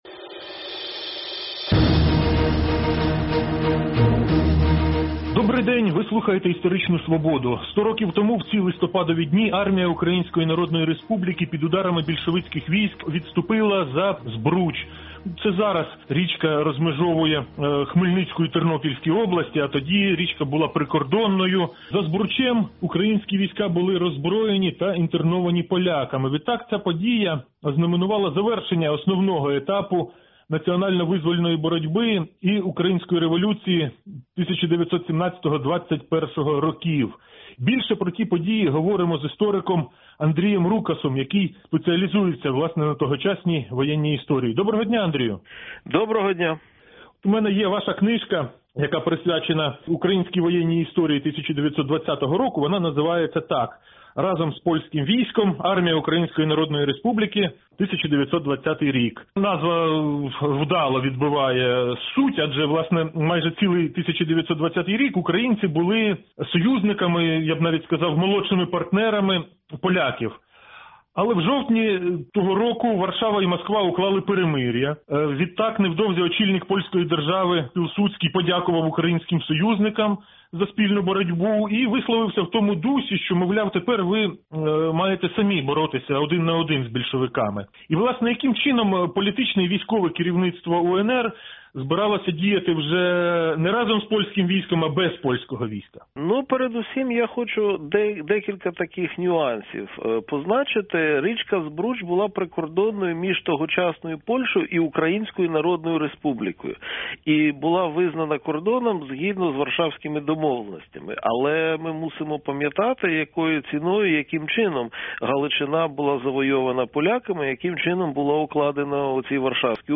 Ця подія ознаменувала завершення основного етапу національно-визвольної боротьби й Української революції 1917–1921 років. Більше про це Радіо Свобода поговорило з істориком